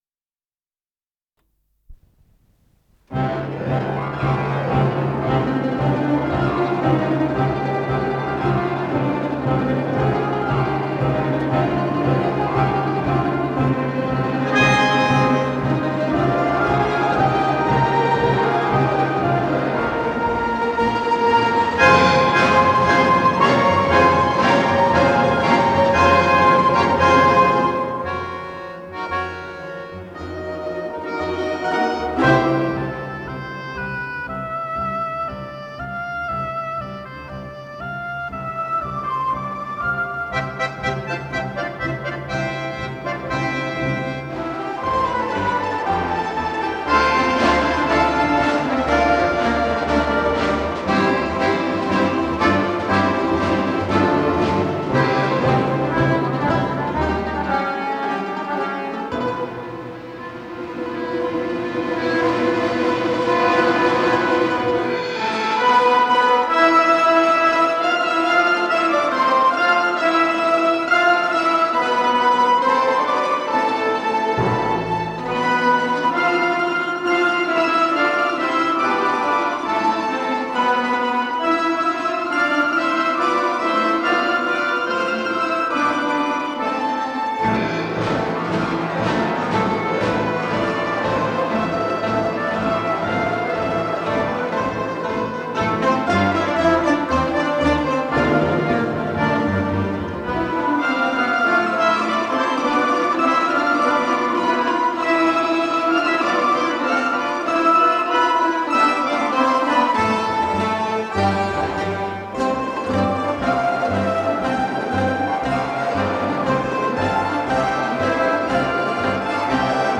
с профессиональной магнитной ленты
до мажор